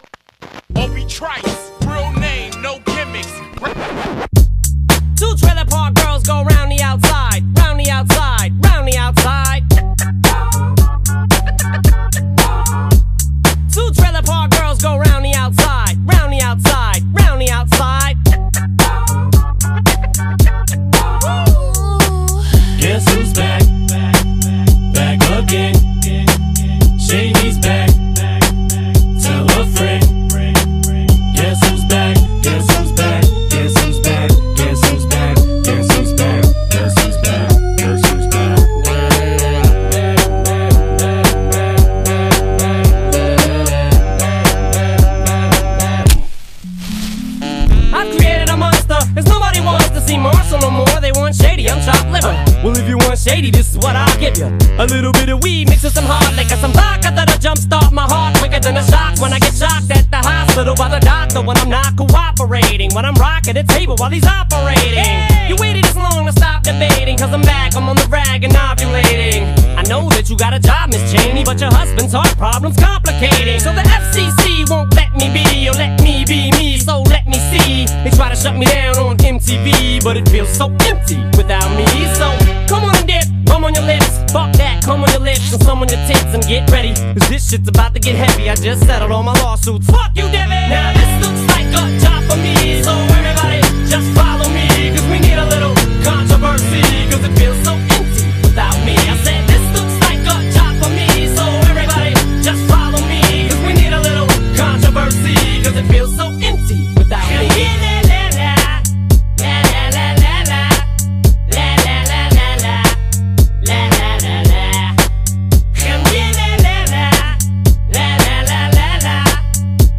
BPM111-113
Audio QualityMusic Cut